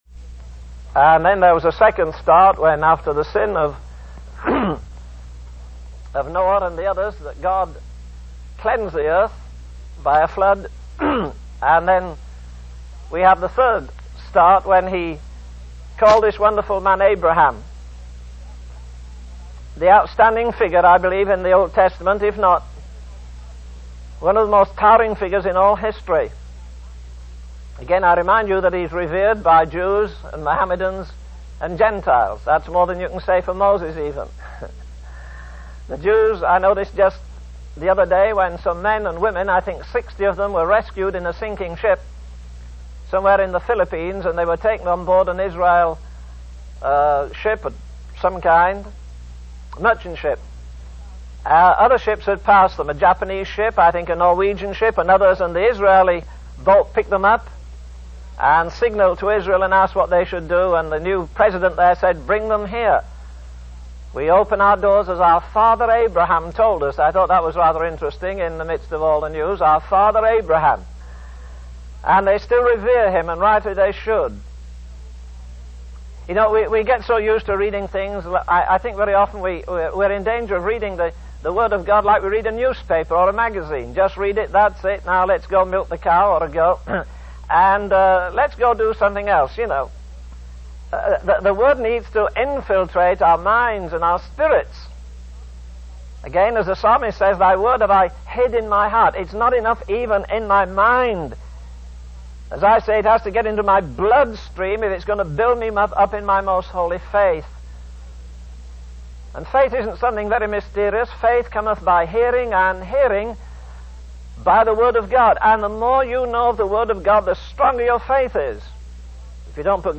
In this sermon, the preacher discusses the events described in the book of Revelation, specifically focusing on the sounding of the trumpets. He describes the darkening of the sun, moon, and stars, as well as the opening of the bottomless pit by a fallen star.